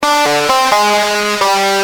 Lead_b7.wav